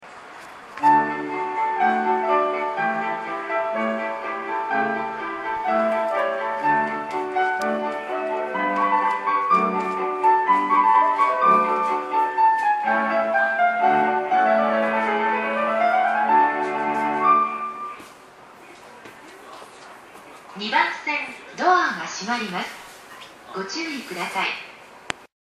２番線常磐線
発車メロディー